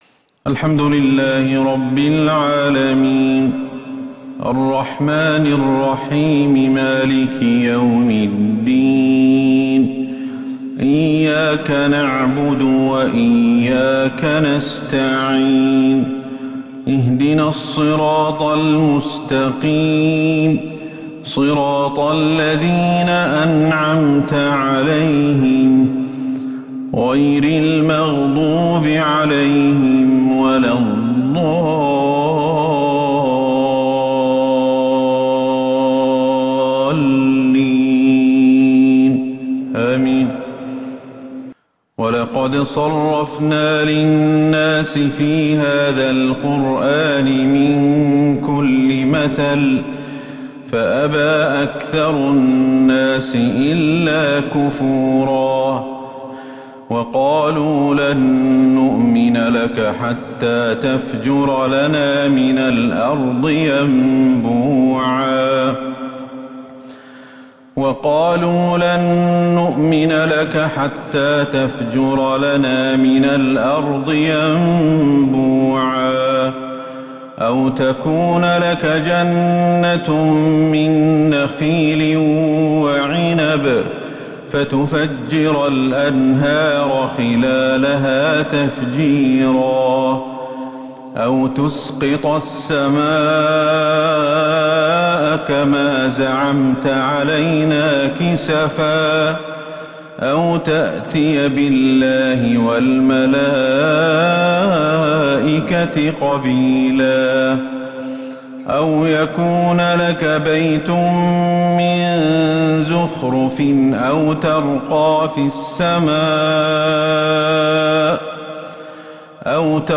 فجر السبت 4-7-1443هـ خواتيم سورة الإسراء | Fajr prayer from surat Al-Isra 5-2-2022 > 1443 هـ > الفروض